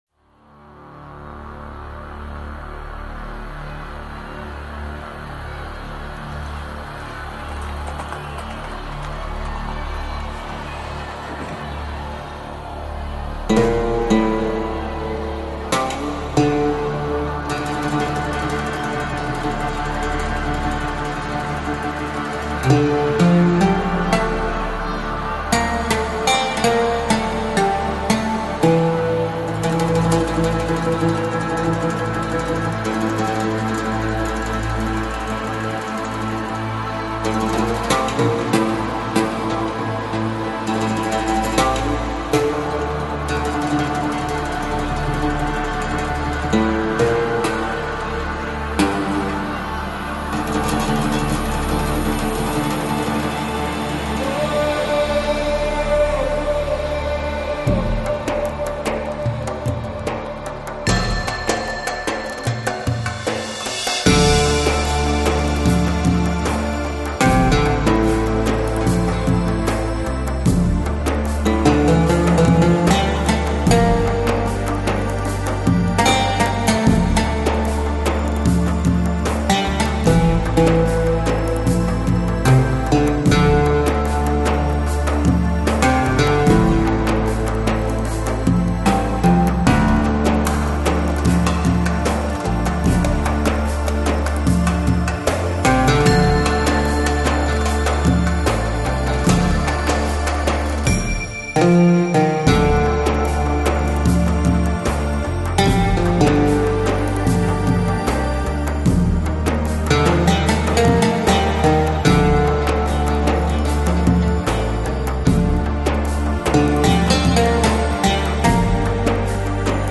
STYLE: New Age, Ethnic